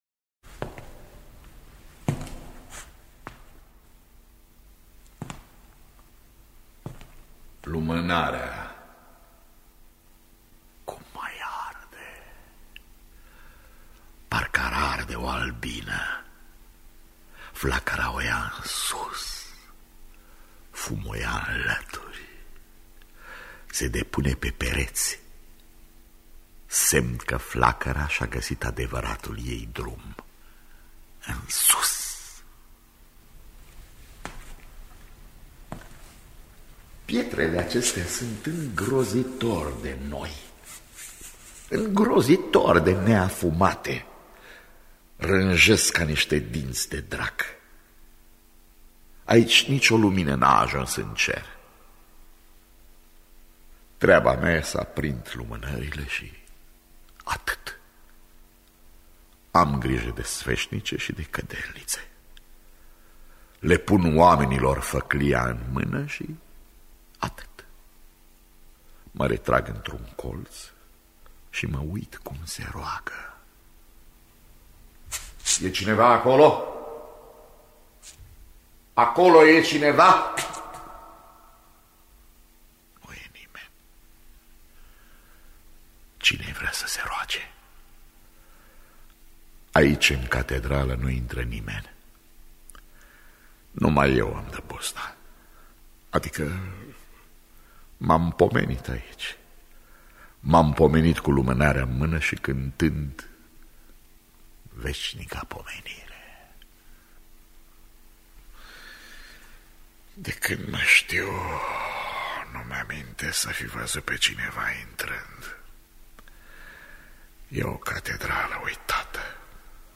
Paracliserul de Marin Sorescu – Teatru Radiofonic Online